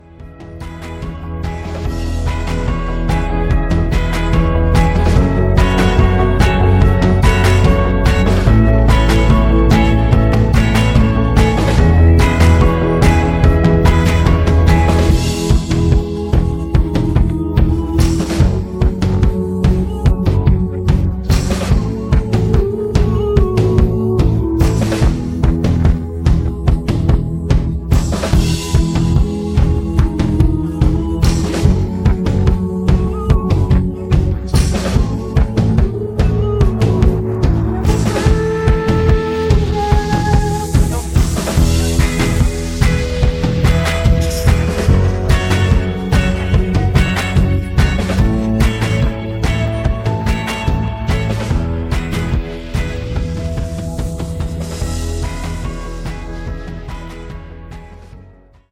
음정 -1키